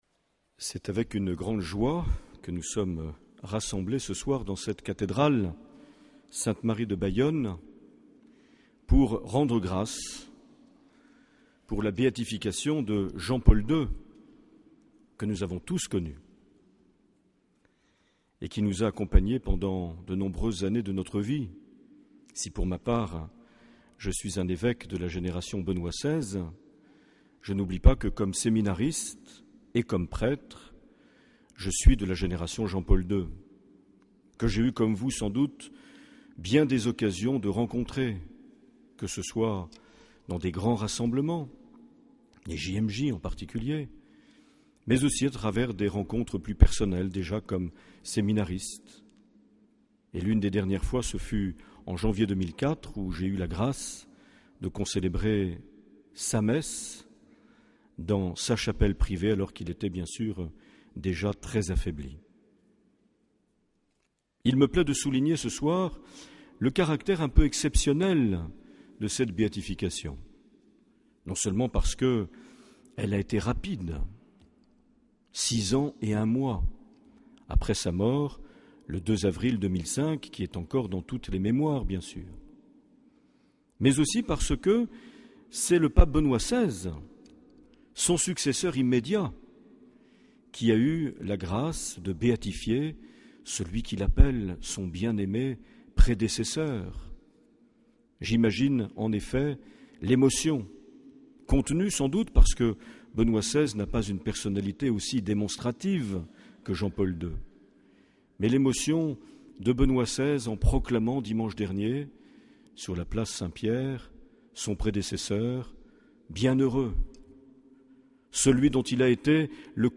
8 mai 2011 - Cathédrale de Bayonne - Messe d’action de grâces pour la béatification de Jean-Paul II
Une émission présentée par Monseigneur Marc Aillet